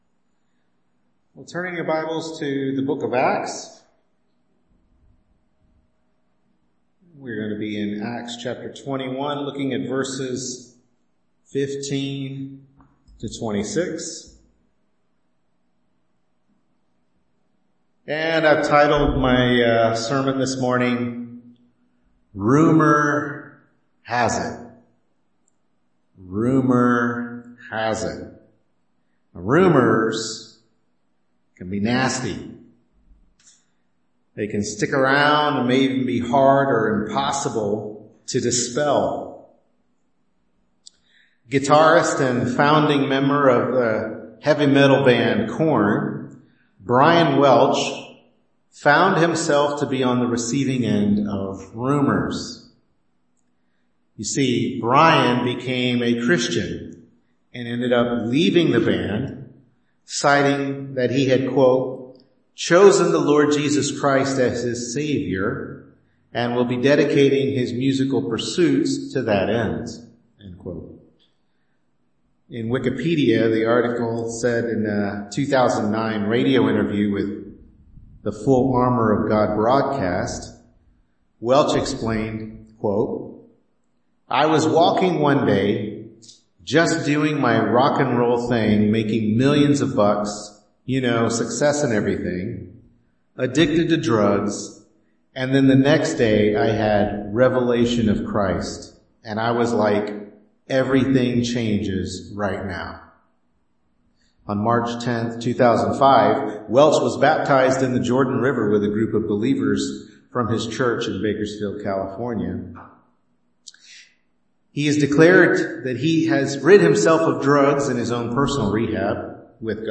Acts 21:15-26 Service Type: Morning Worship Service Bible Text